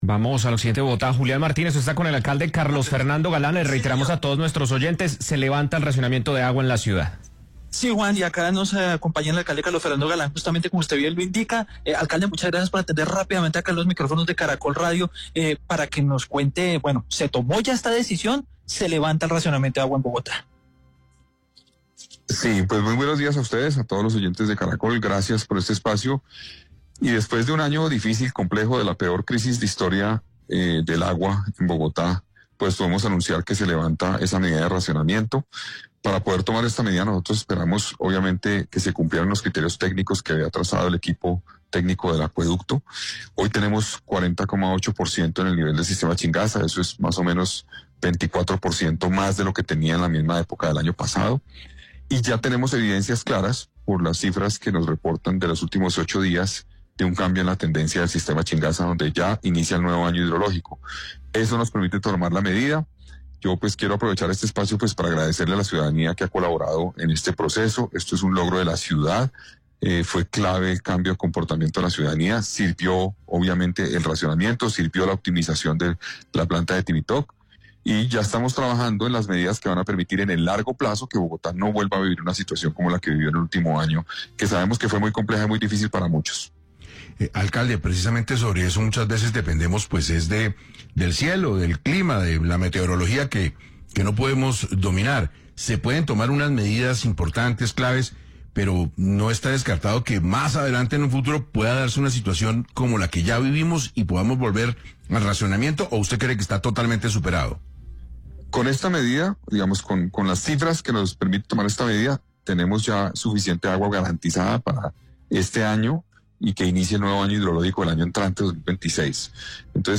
En 6AM de Caracol Radio, el alcalde de Bogotá, Carlos Fernando Galán dijo que la decisión se tomó luego de que los datos suministrados por el acueducto cumplieran con lo que se esperaba para levantar el racionamiento.